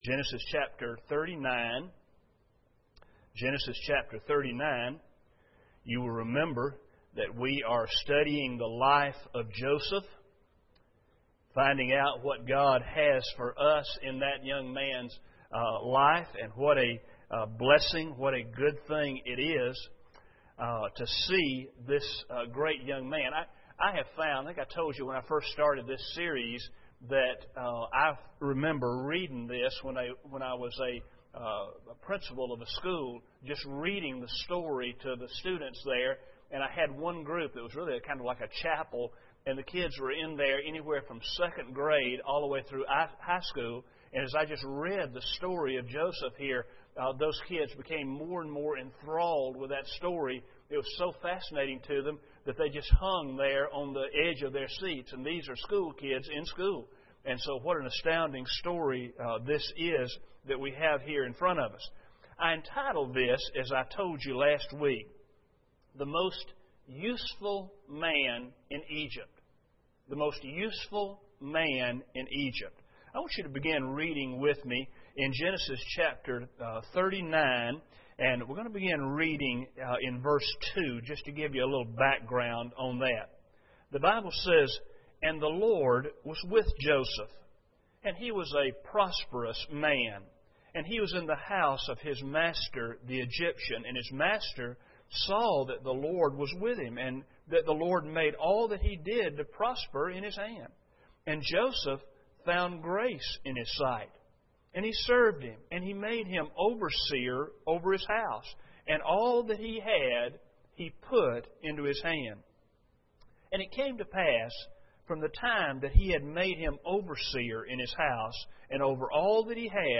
Life of Joseph Service Type: Sunday Evening Preacher